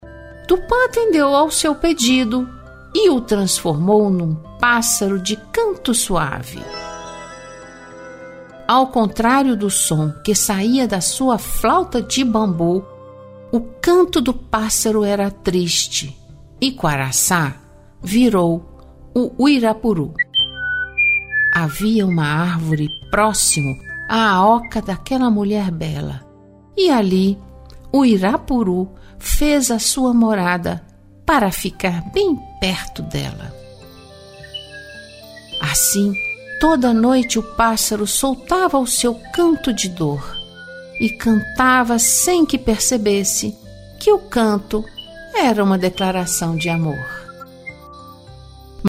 Audiolivro – A lenda do uirapuru: recontada em versos